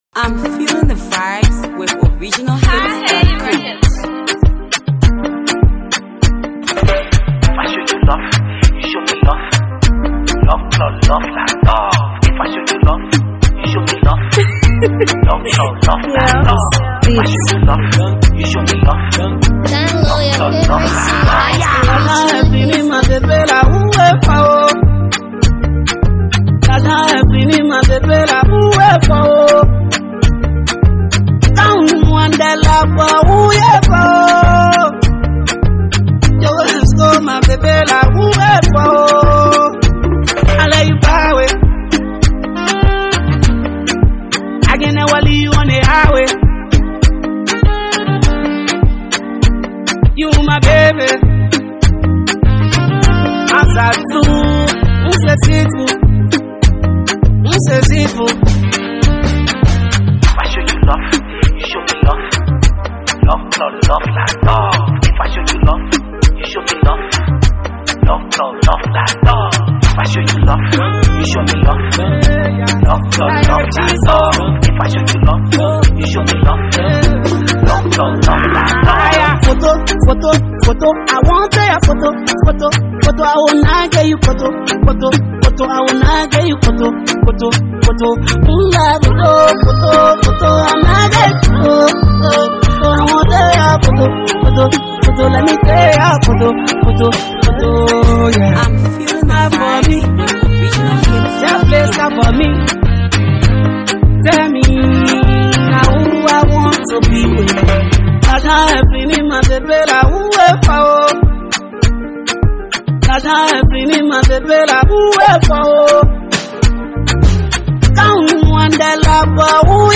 London Vibes !